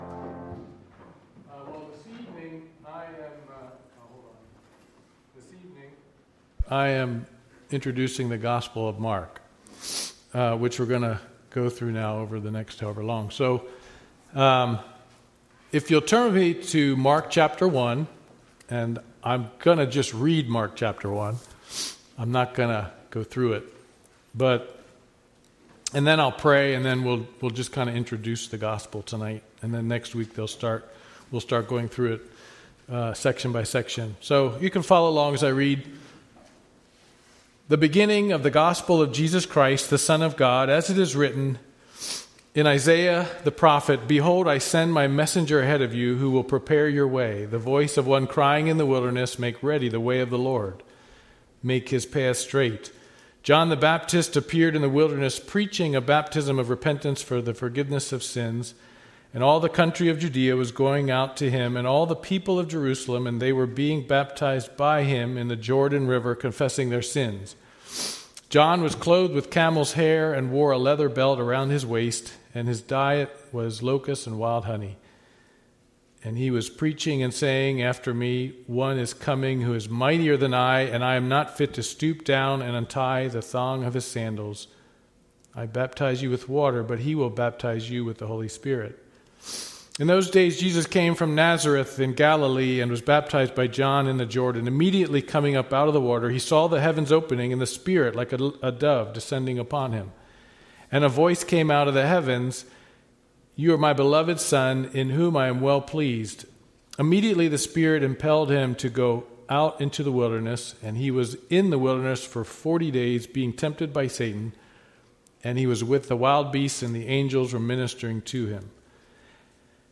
Mark Service Type: Wednesday Devotional « God’s Providence and Man’s Sin WMBS